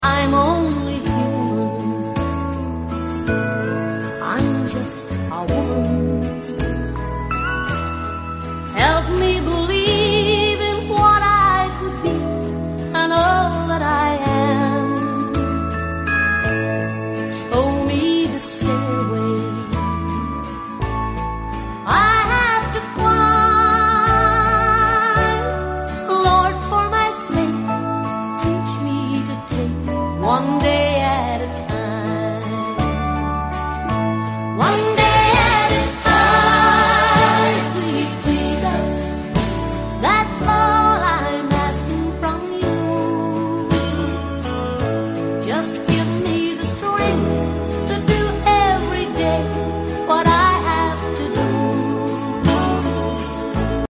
Worship music.